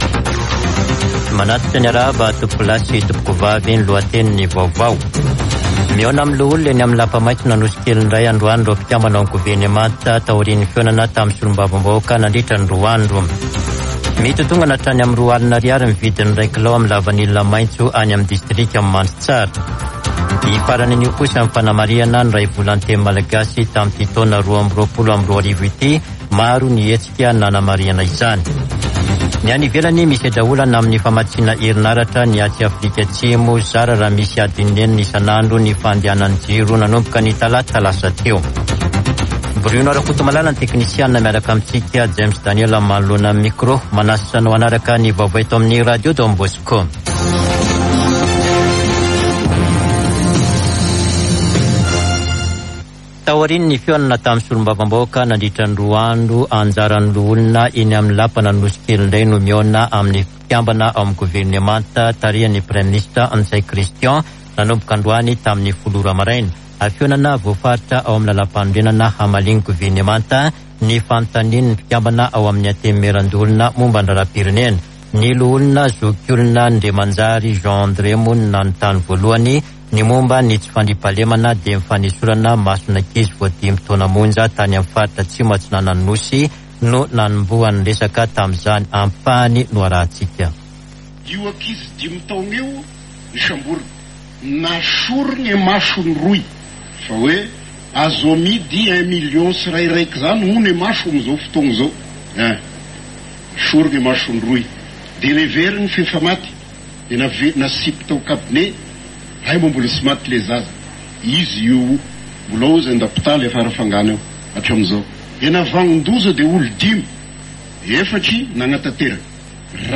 [Vaovao antoandro] Alakamisy 30 jona 2022